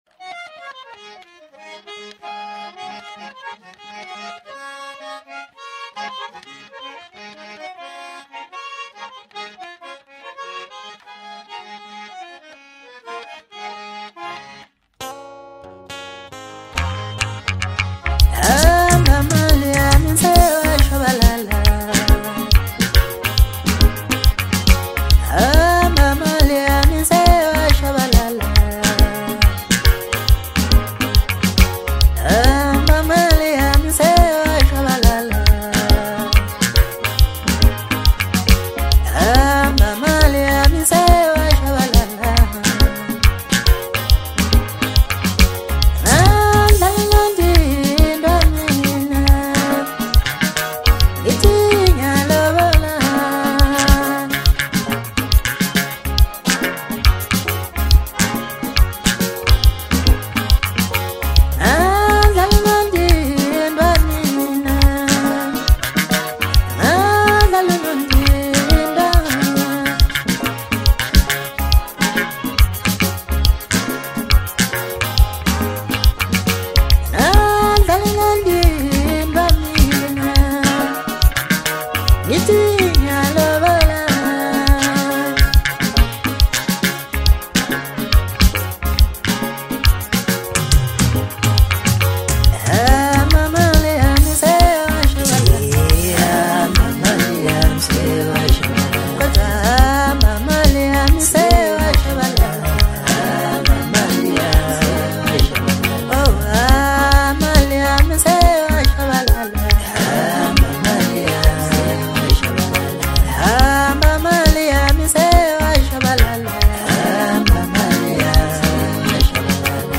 Home » Maskandi » DJ Mix » Hip Hop
South African singer-songsmith